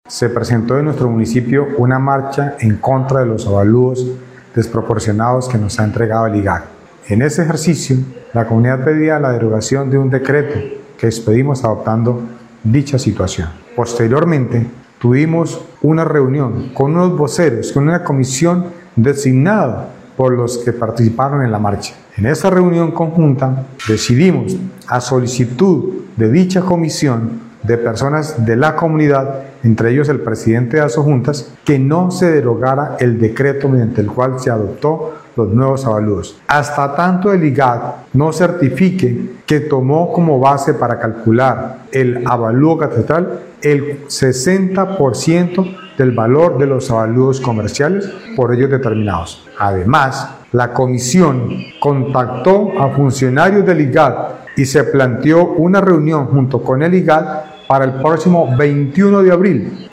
Arbenz Pérez Quintero, alcalde del municipio de Valparaíso, dijo que, ese día será la oportunidad de poder resolver dudas e inquietudes sobre el avalúo y la manera como se hizo, donde al parecer hubo y se cometieron varios errores.
ALCALDE_ARBENZ_PEREZ_DECRETO_-_copia.mp3